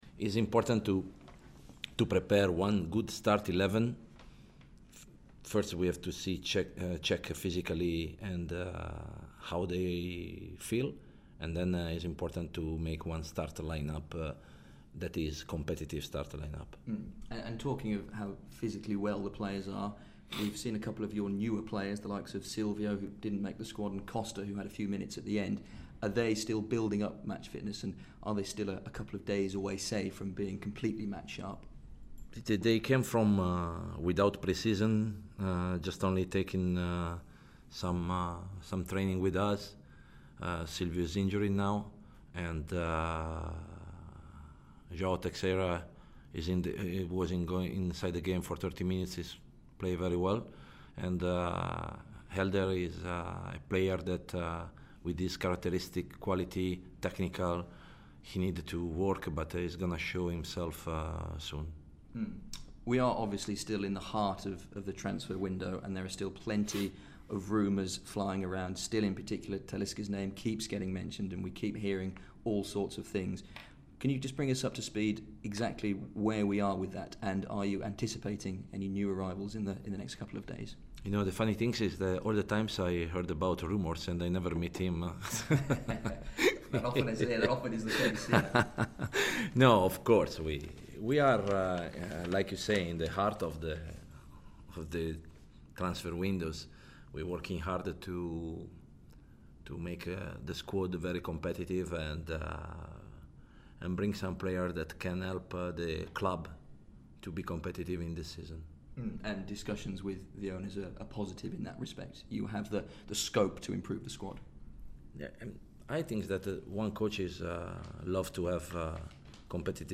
Head coach Walter Zenga speaks to BBC WM ahead of the EFL Cup clash at home to Crawley